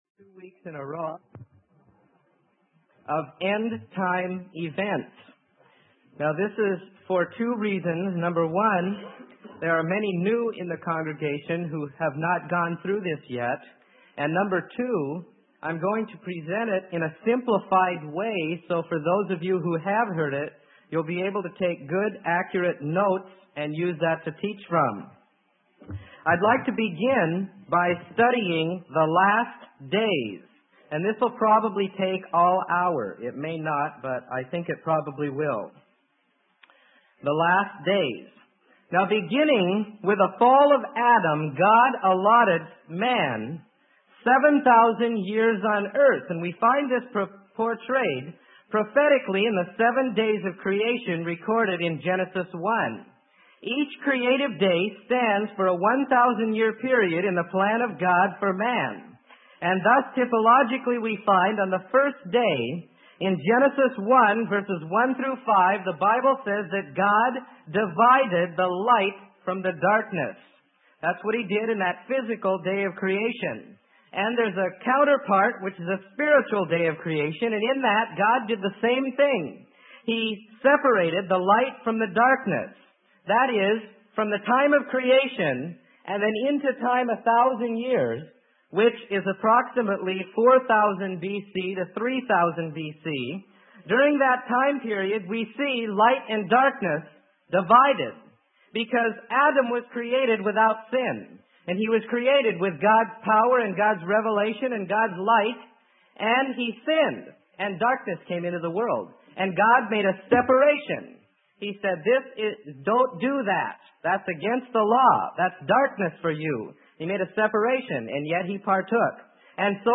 Sermon: End Time Events - Part 1 - Freely Given Online Library